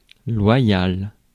Ääntäminen
IPA: [lwa.jal]